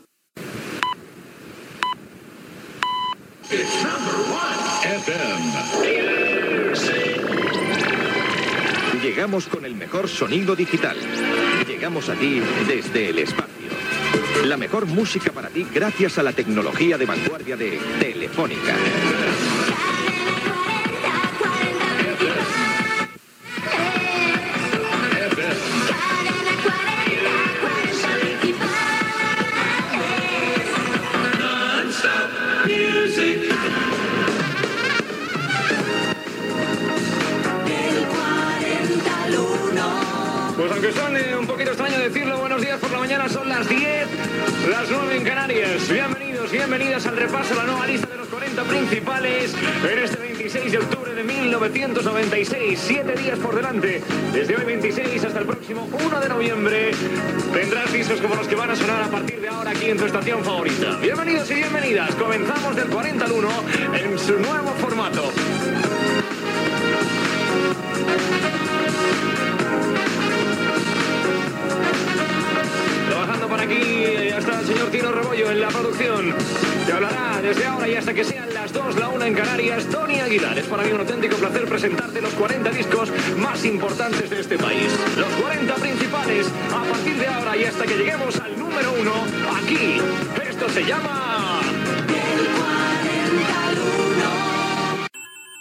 Indicatiu de la ràdio, careta del programa, data i presentació
Musical
Aguilar, Tony
FM